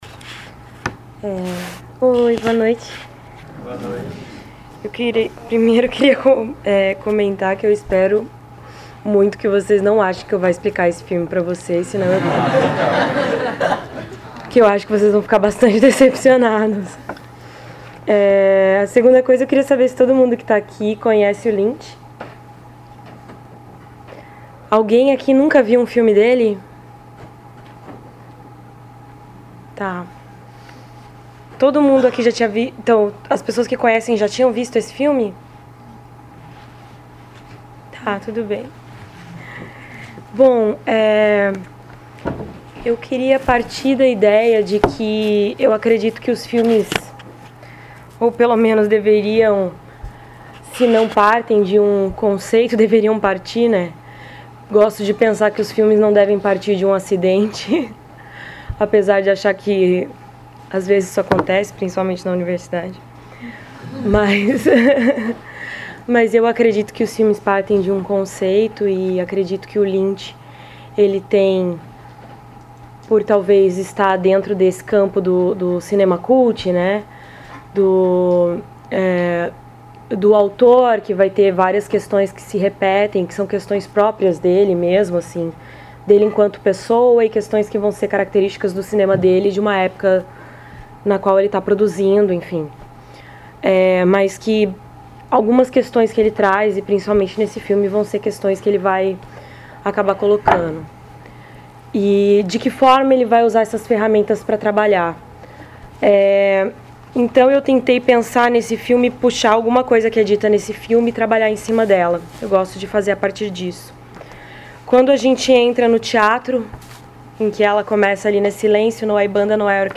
realizada em 02 de junho de 2016 no Auditório "Elke Hering" da Biblioteca Central da UFSC.